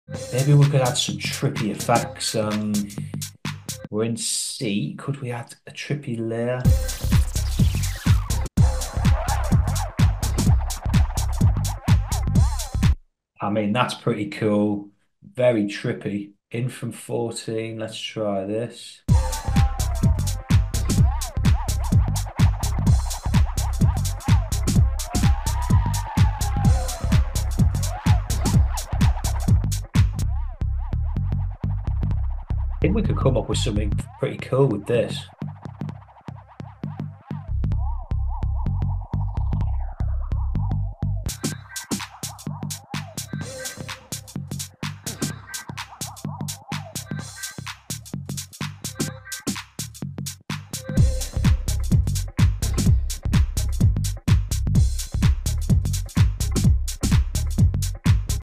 ElectraX is a wkd plug in for trippy sounding layers 🔥 It’s got a lot of amazing synths and musical elements but we often use it for cool effect sounds to fill out the track. We add some cool layers to the Apollonia influenced groove. After hours vibes on this one 💥 Full breakdown on creating this track available to members now on the site ✨ ElectraX Is A Wkd Plug Sound Effects Free Download.